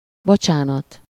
Ääntäminen
IPA: /de.zɔ.le/